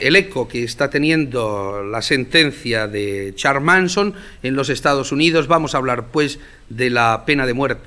Careta de sortida